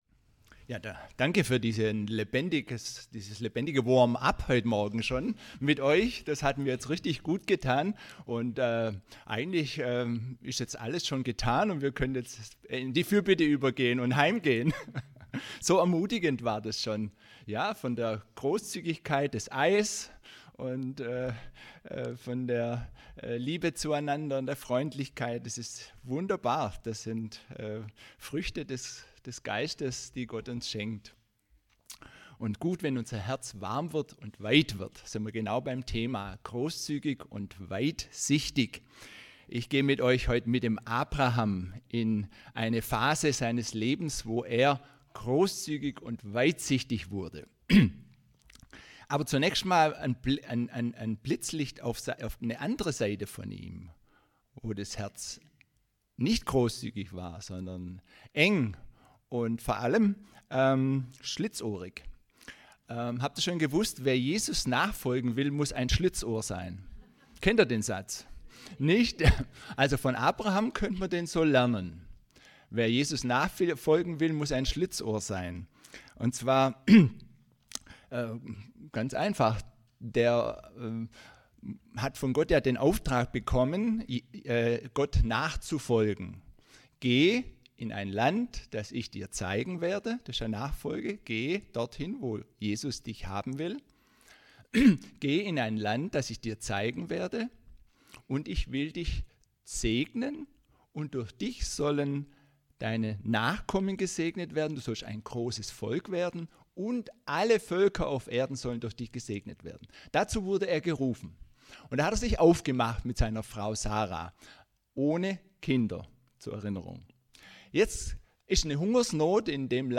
Predigten – Oase Gemeinde